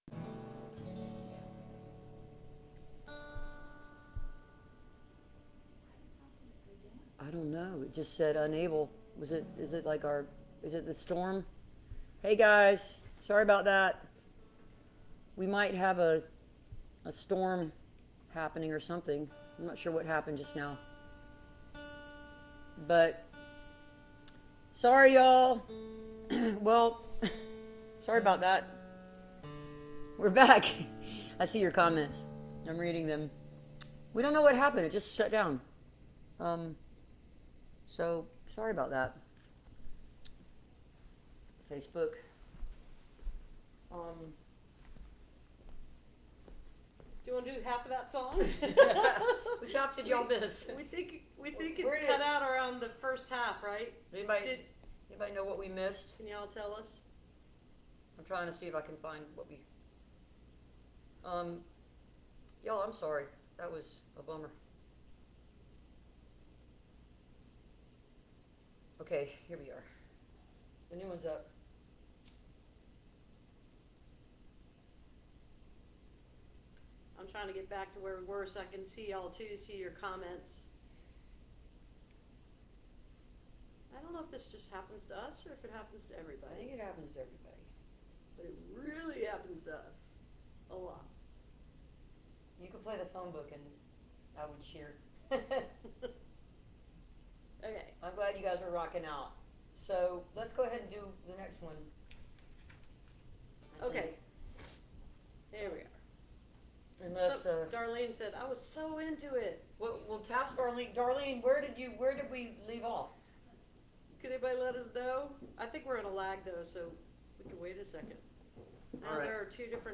(captured from the facebook livestream)
08. talking with the crowd (2:34)